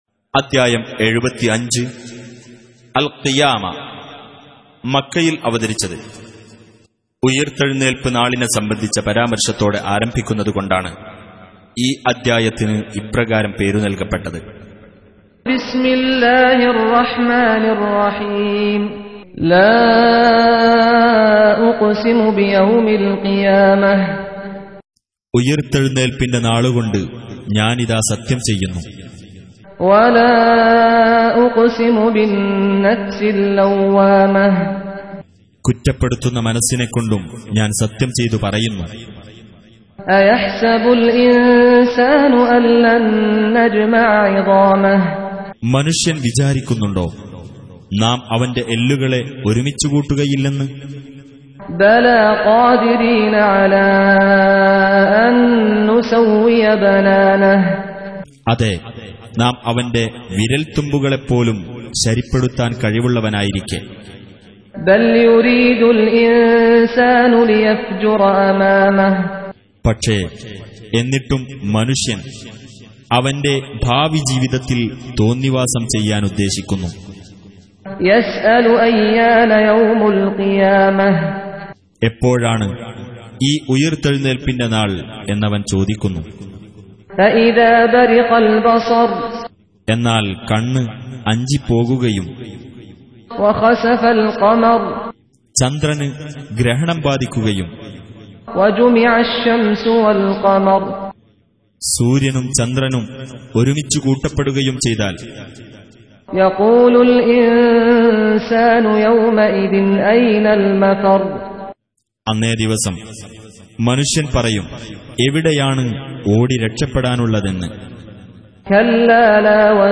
75. Surah Al-Qiy�mah سورة القيامة Audio Quran Tarjuman Translation Recitation
EsinIslam Audio Quran Recitations Tajweed, Tarteel And Taaleem.